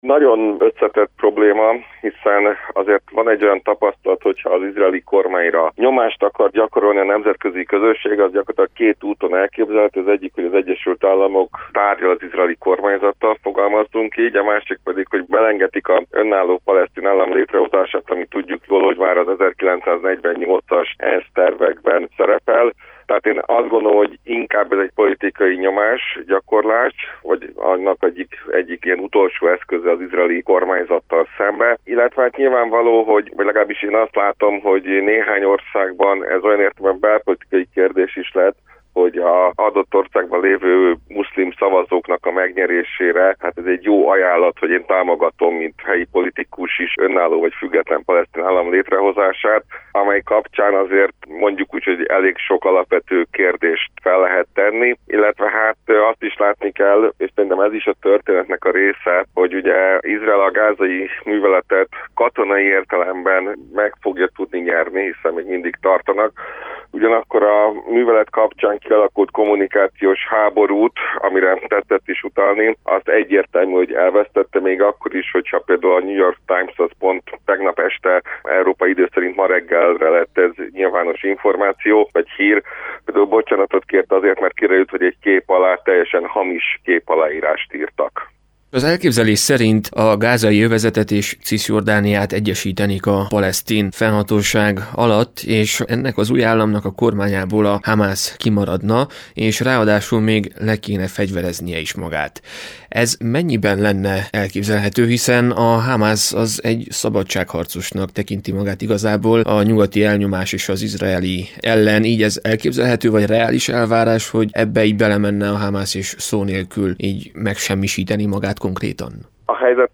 Közel-Kelet szakértő.